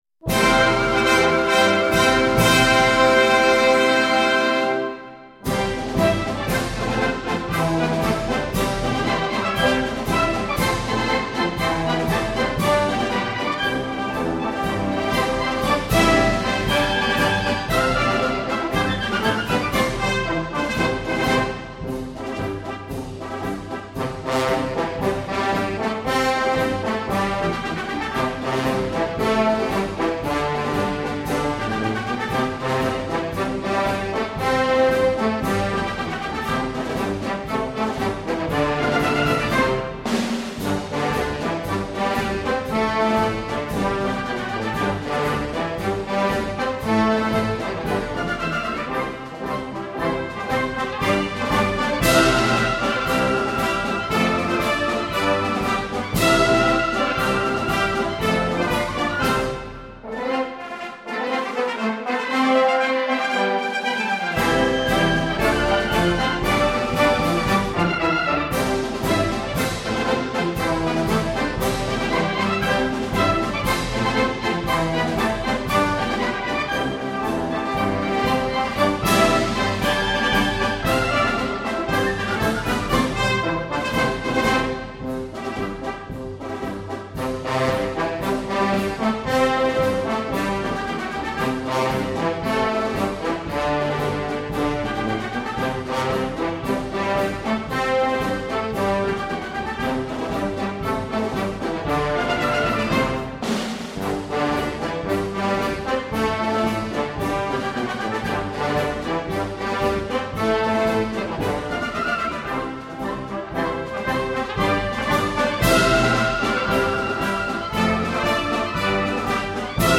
铜管乐合奏音响丰满、节奏铿锵、鼓舞人心，用于公共场合常能充分展示一个国家的国威与军威。